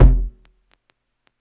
KICK 4.wav